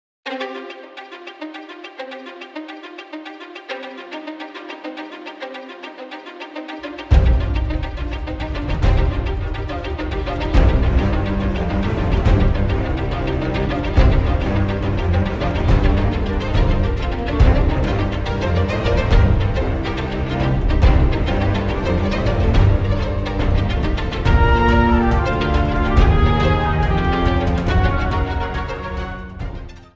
Trimmed to 30 seconds, with a fade out effect